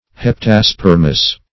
Search Result for " heptaspermous" : The Collaborative International Dictionary of English v.0.48: Heptaspermous \Hep`ta*sper"mous\, a. [Hepta- + Gr.
heptaspermous.mp3